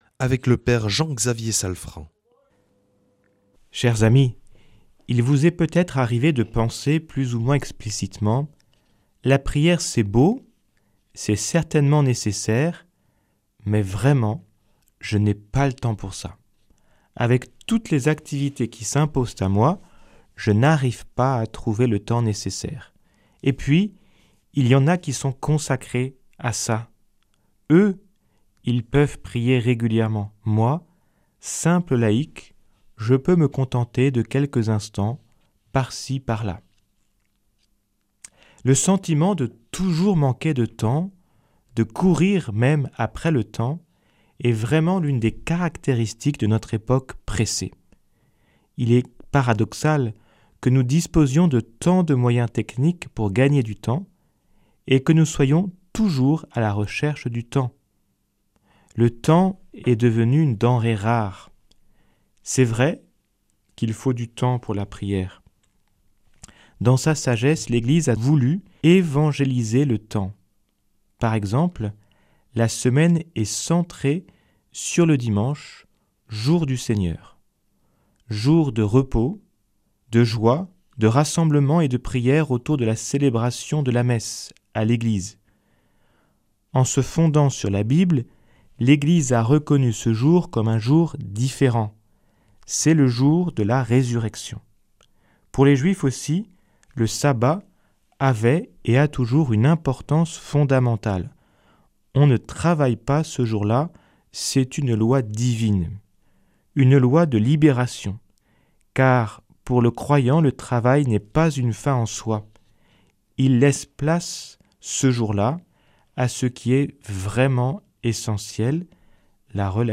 jeudi 8 janvier 2026 Enseignement Marial Durée 10 min